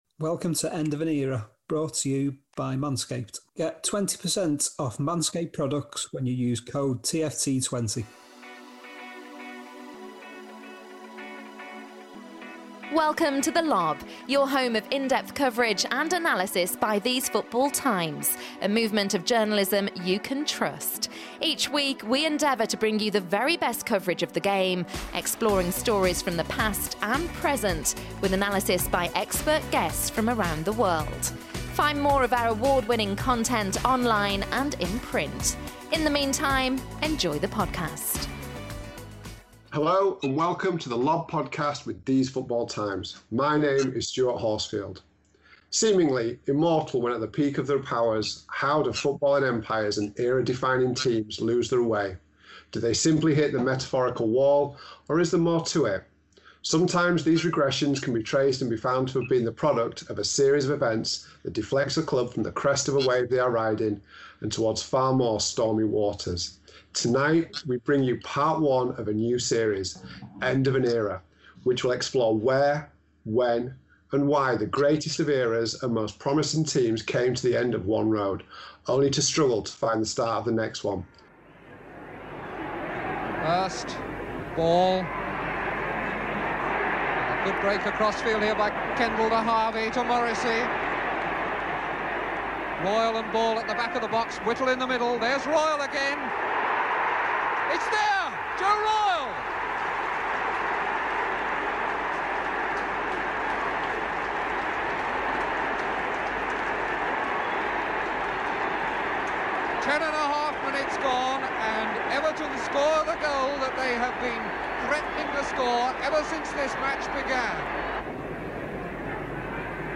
Join us for episode one of our new series, End of an Era, where a guest picks a team of choice to discuss how and why a defined era of success ended.